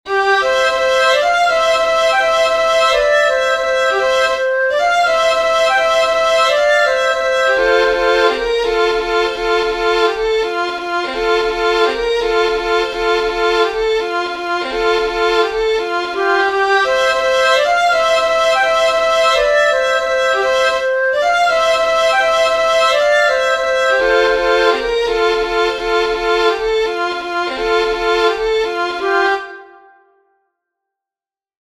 Musiche digitali in mp3 tratte dagli spartiti pubblicati su
Raccolta e trascrizioni di musiche popolari resiane